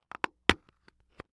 household
Case Plastic Small Open